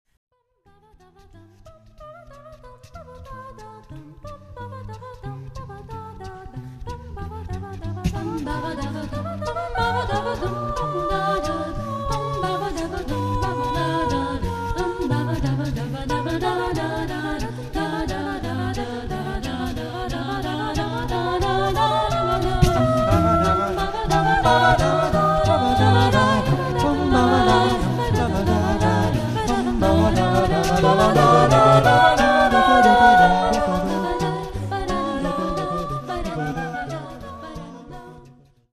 Il sestetto vocale "Canto libero" è nato per iniziativa
Il sestetto è composto da:
direttore e percussioni vocali